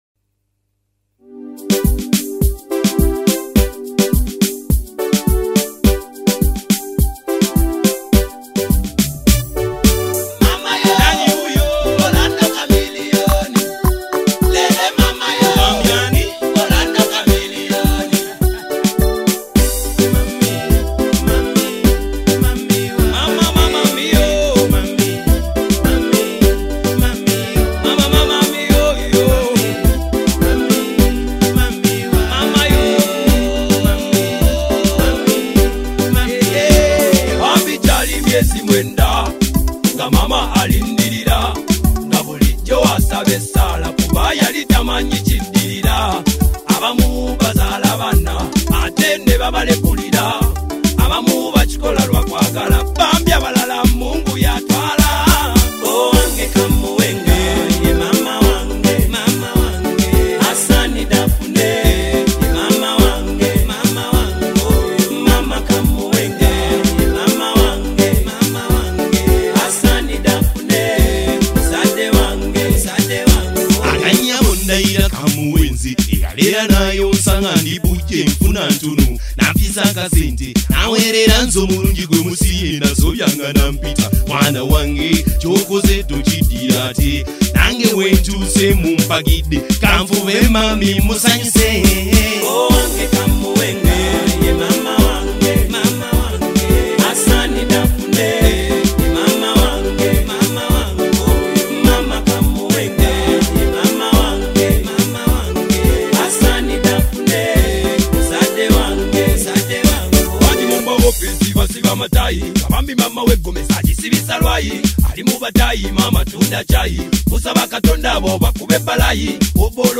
Genre: Oldies